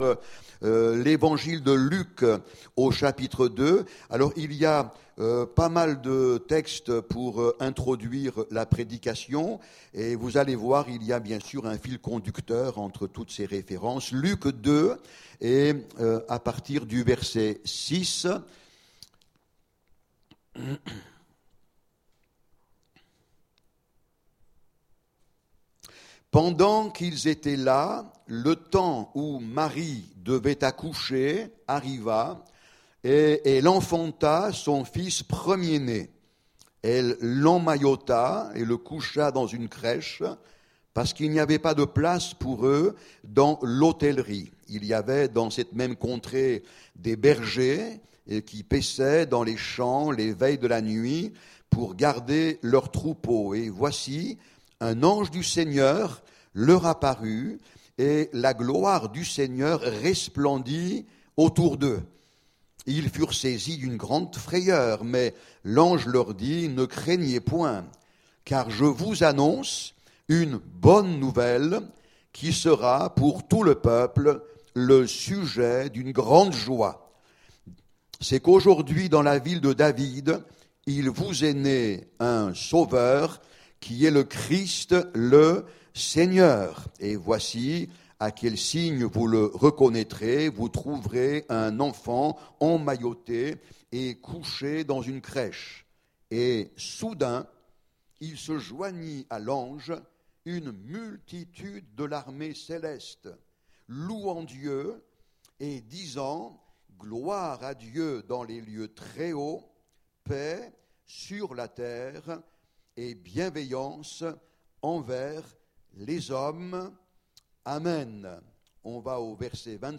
Puissance de Dieu Prédicateur